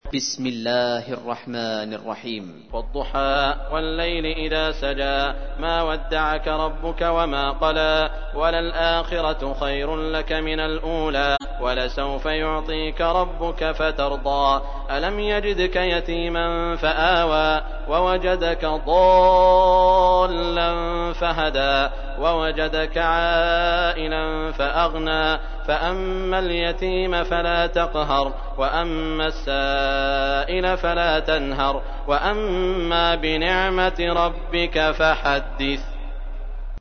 تحميل : 93. سورة الضحى / القارئ سعود الشريم / القرآن الكريم / موقع يا حسين